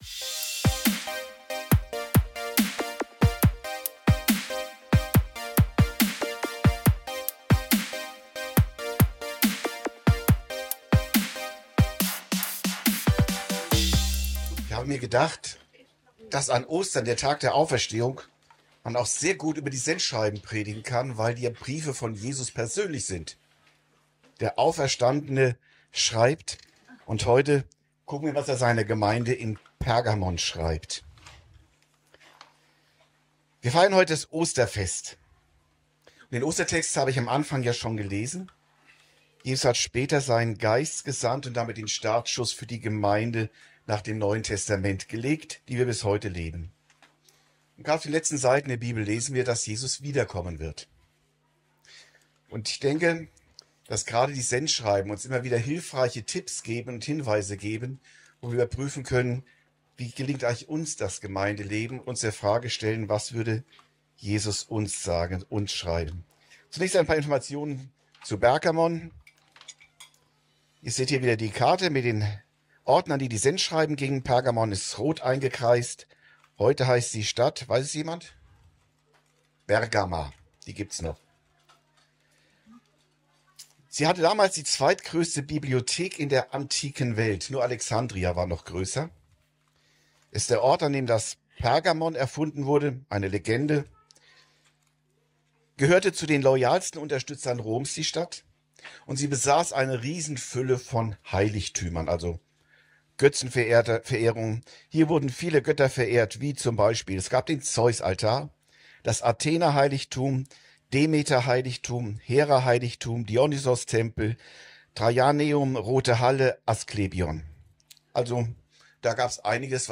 Brief an die Gemeinde am Thron Satans (Pergamon) ~ Predigten u. Andachten (Live und Studioaufnahmen ERF) Podcast
Predigt über die Offenbarung / die Sendschreiben